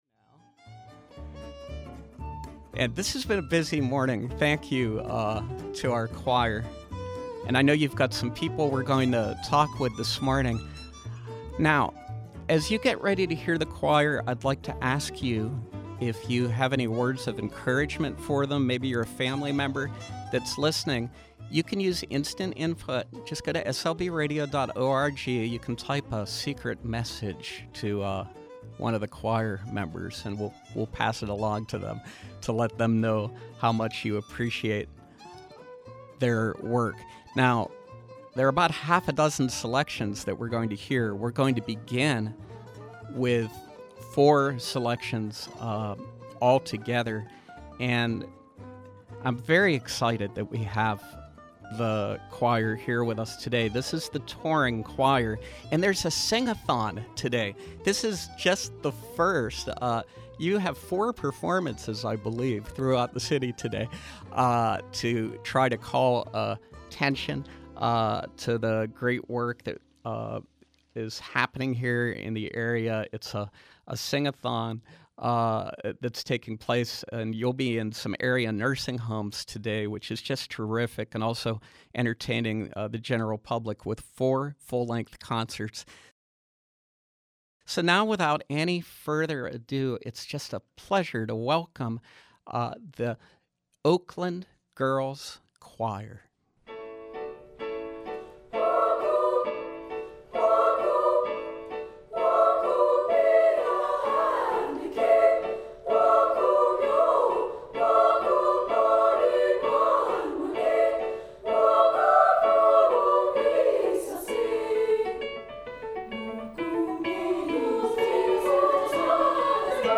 From 12/1/12: Oakland Girls Choir Concentio Choir with challenging, classical, multiple-part pieces featuring thirty-five 6th through 12 grade girls from throughout Pittsburgh. The choir previews their Sing-a-thon, bringing music to area nursing homes and the general public via four full-length concerts, all on 12/1